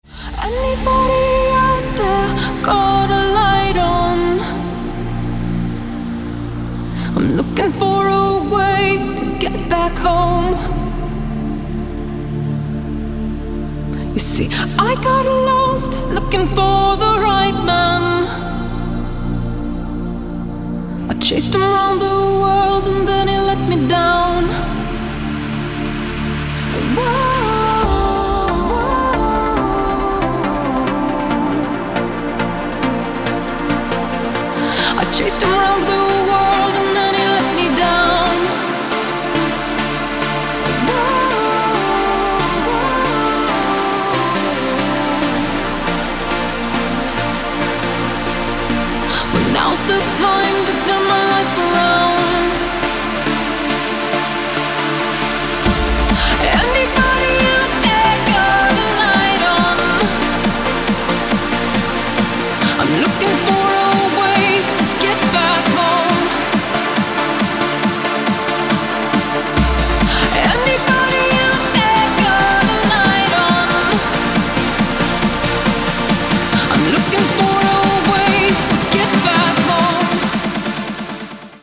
• Качество: 192, Stereo
громкие
dance
Electronic
электронная музыка
нарастающие
club
красивый женский голос
Trance